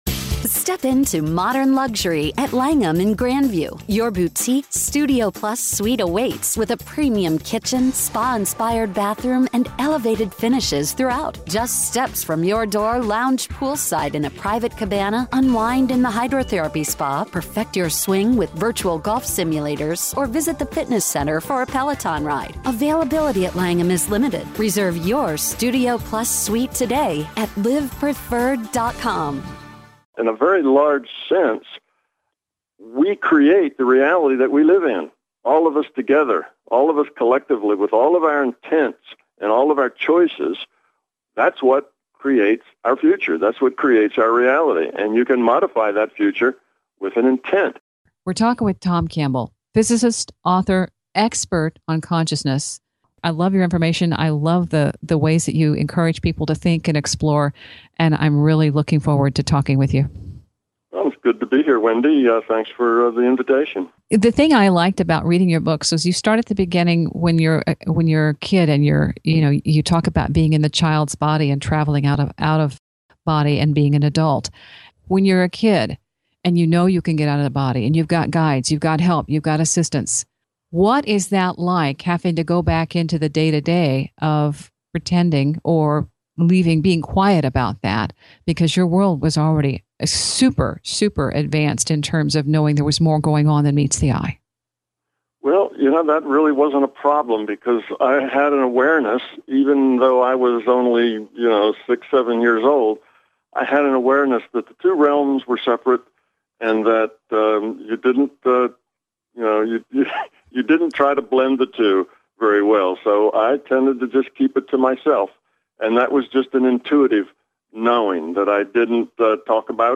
My interview